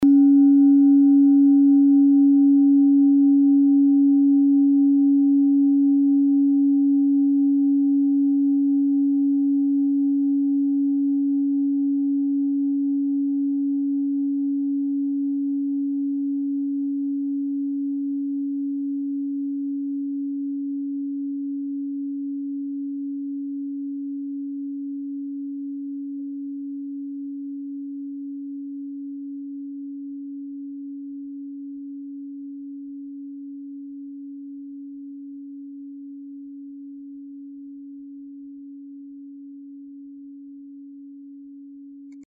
Klangschale TIBET Nr.3
Klangschale-Gewicht: 840g
Klangschale-Durchmesser: 16,1cm
Sie ist neu und ist gezielt nach altem 7-Metalle-Rezept in Handarbeit gezogen und gehämmert worden..
Die Frequenz des Plutotons liegt bei 140,25 Hz und dessen tieferen und höheren Oktaven. In unserer Tonleiter liegt er nahe beim "Cis".
klangschale-tibet-3.mp3